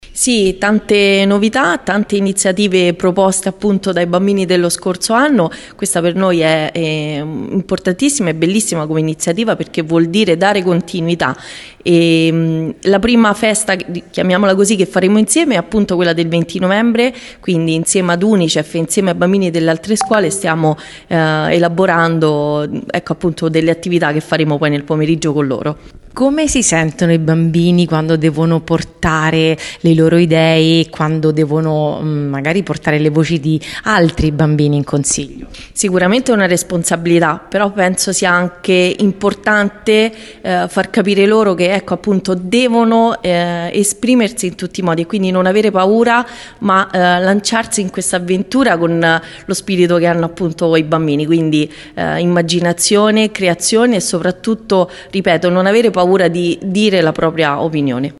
LATINA – Rinnovato questa mattina il consiglio dei bambini e delle bambine del Comune di Latina.
L’Assessora Francesca Tesone